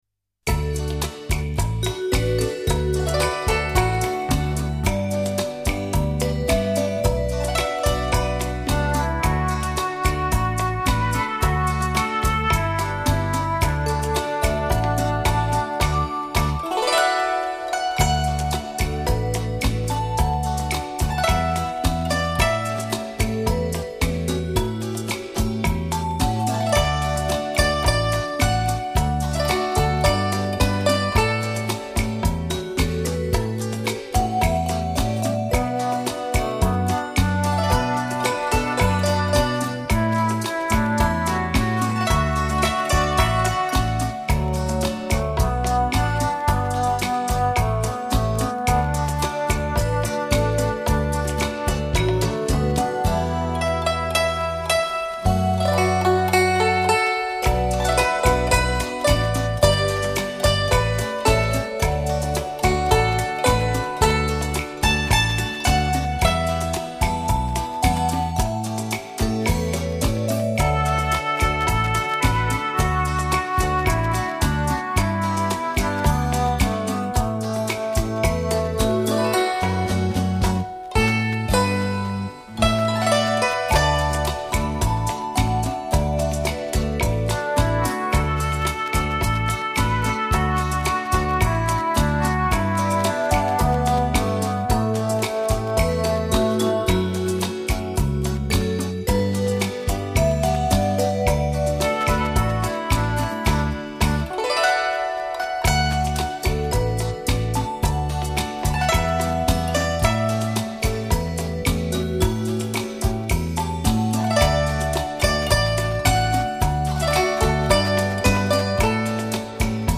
筝与乐队演奏的日本乐曲
合成器